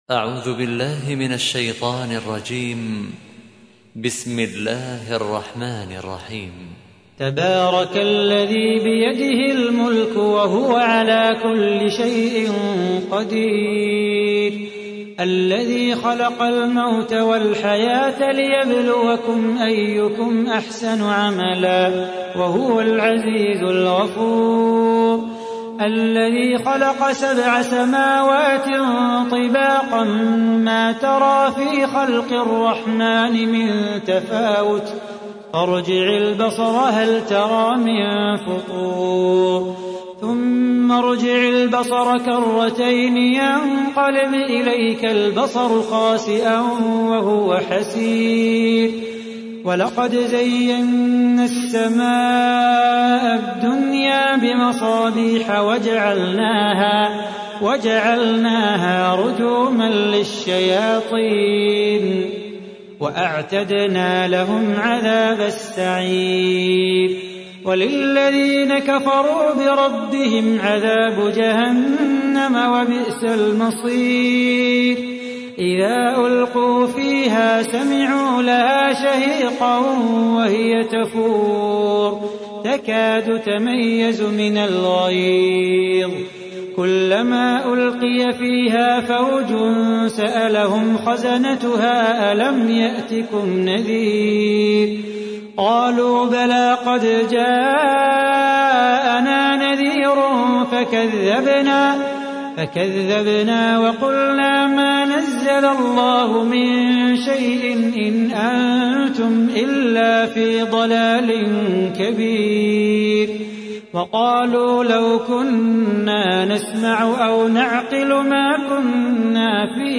تحميل : 67. سورة الملك / القارئ صلاح بو خاطر / القرآن الكريم / موقع يا حسين